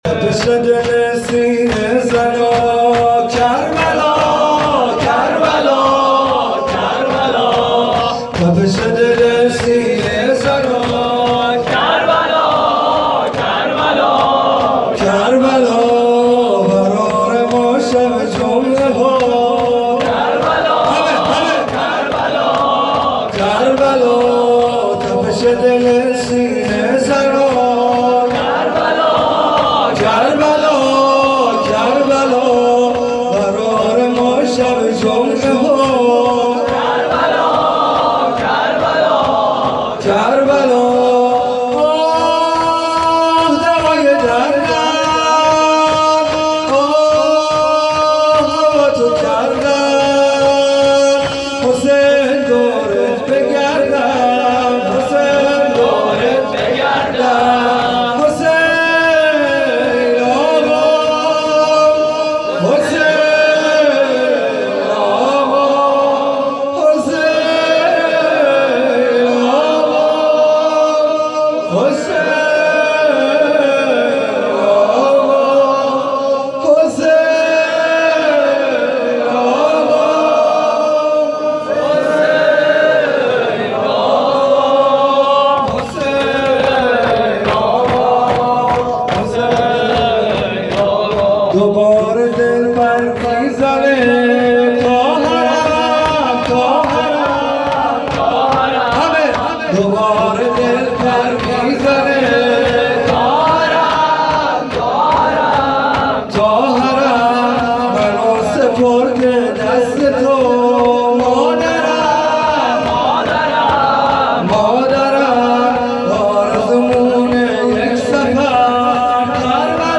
شور
توضیحات: هیئت صادقیون حوزه علمیه زابل